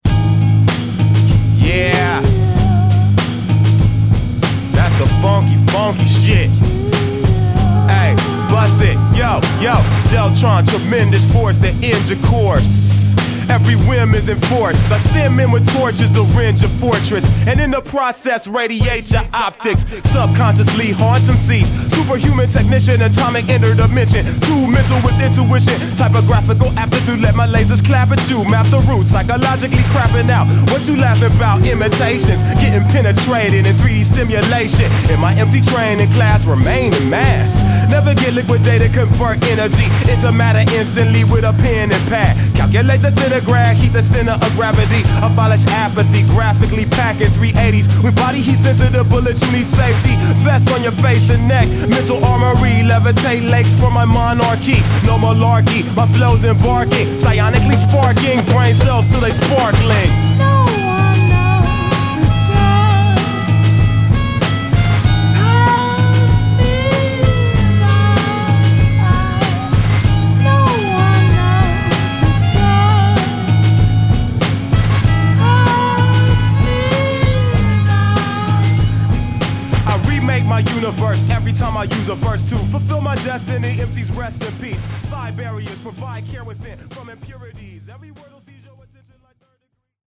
extremely funky
lacing scratches and vocal samples throughout every song